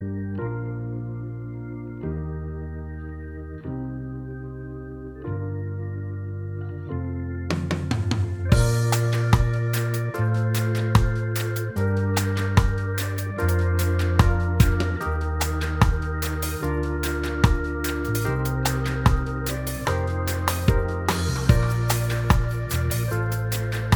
Minus All Guitars Pop (2010s) 4:24 Buy £1.50